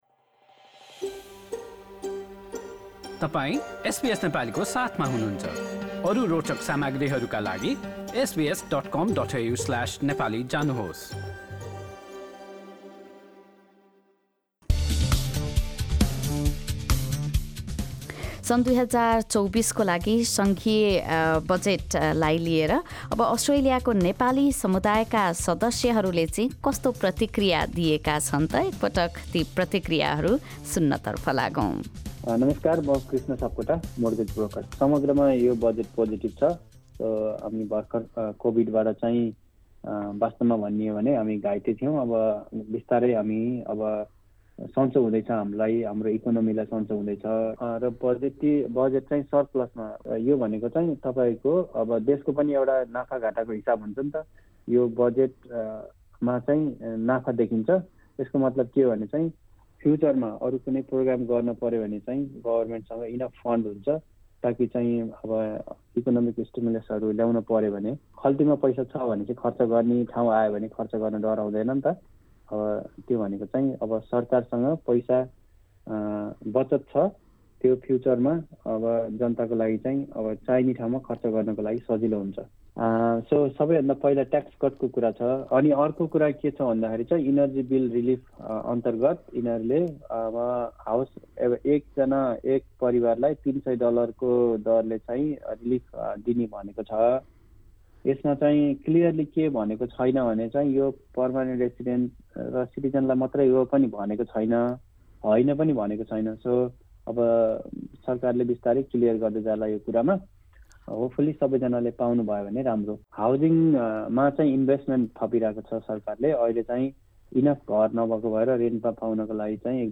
Nepali community members give their response to the federal budget.